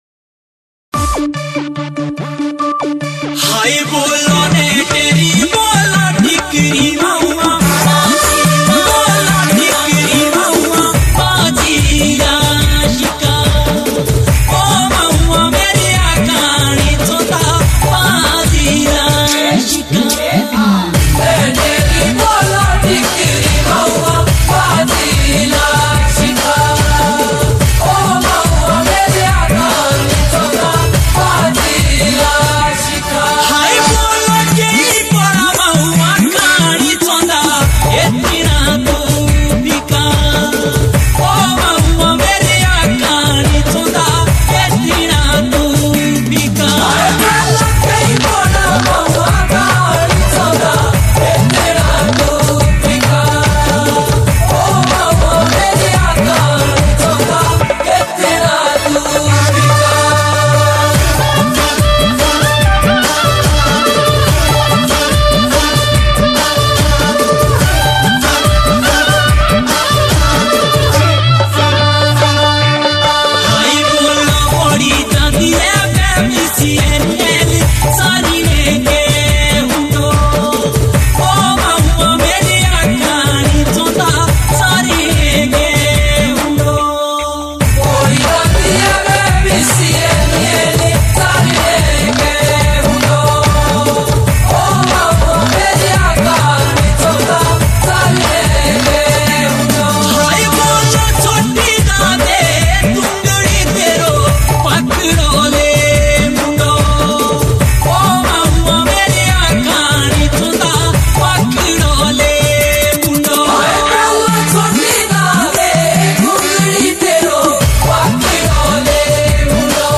Himachali Songs